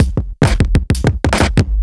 OTG_Kit8_Wonk_130b.wav